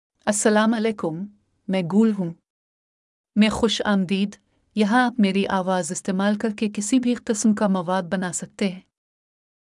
GulFemale Urdu AI voice
Gul is a female AI voice for Urdu (India).
Voice sample
Listen to Gul's female Urdu voice.
Female